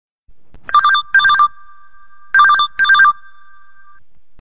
Category: Message Tones